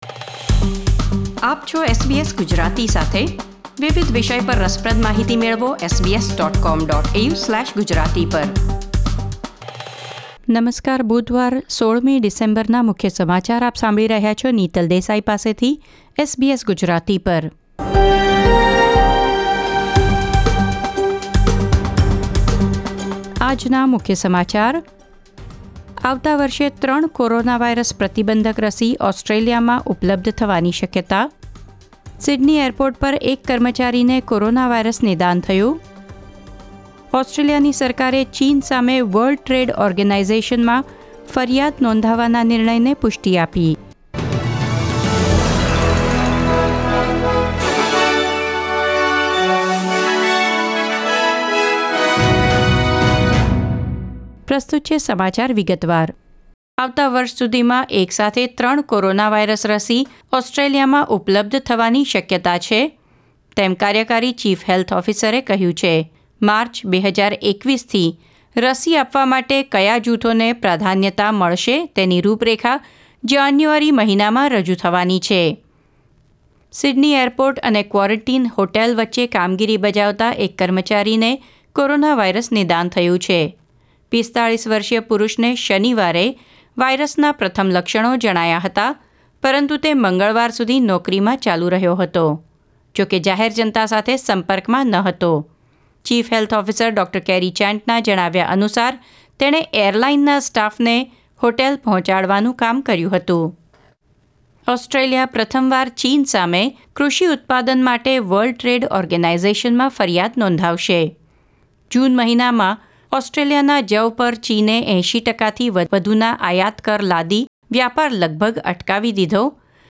SBS Gujarati News Bulletin 16 December 2020